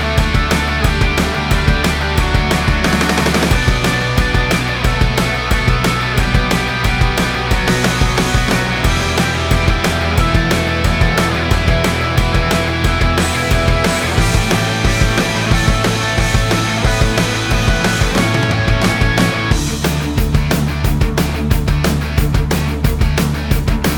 no Backing Vocals Indie / Alternative 3:07 Buy £1.50